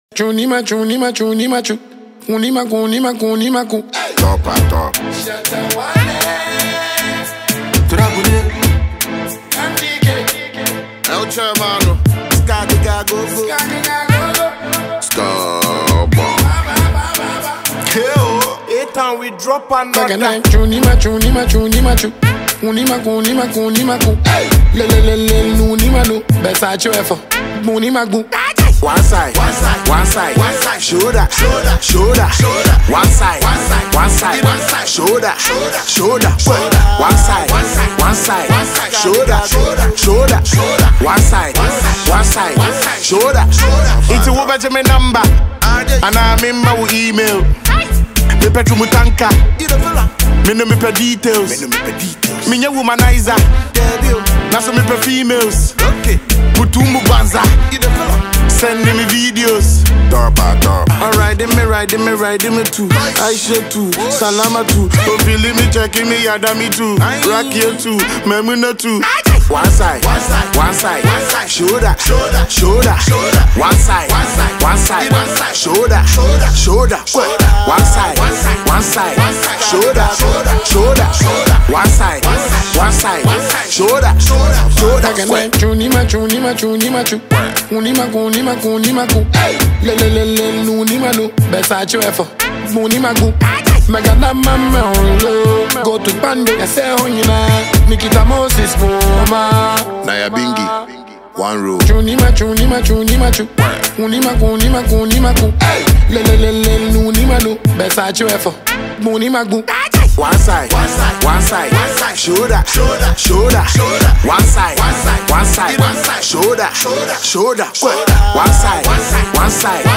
a powerful street anthem